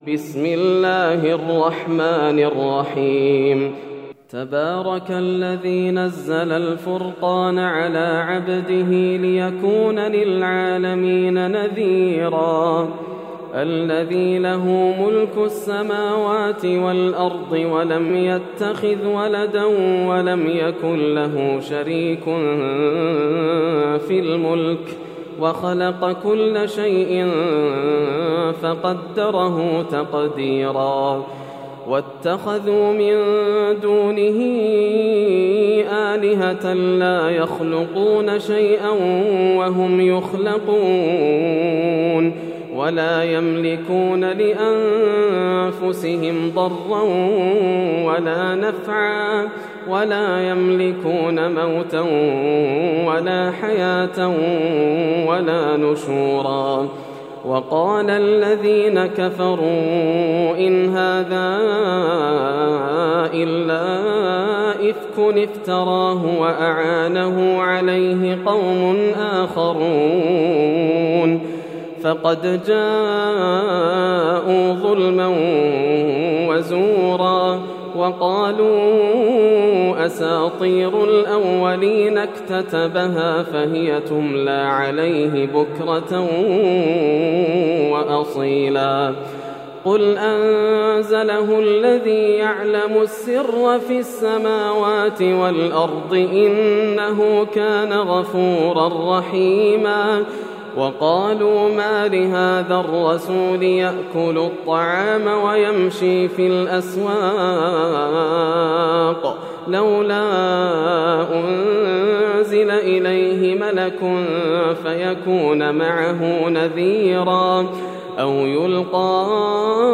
سورة الفرقان > السور المكتملة > رمضان 1431هـ > التراويح - تلاوات ياسر الدوسري